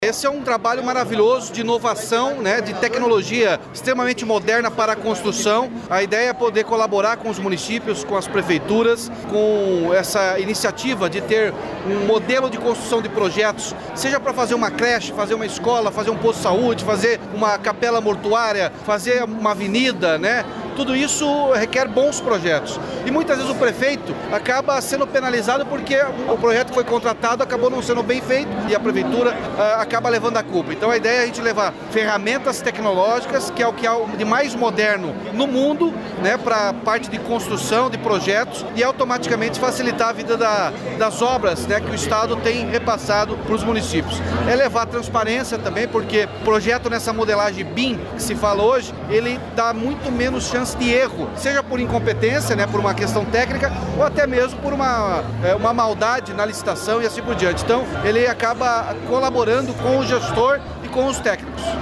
Sonora do governador Ratinho Junior sobre o lançamento do programa para capacitar municípios com metodologia que agiliza obras